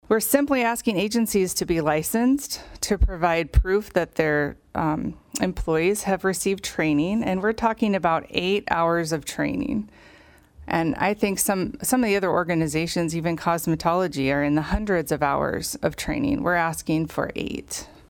District 7 state Rep. Mellissa Heermann, R-Brookings was the sponsor of House Bill 1138 and said this would’ve protected seniors who rely on non-medical home care assistance…